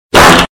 Fart-1-Bass-Boosted-1-1.mp3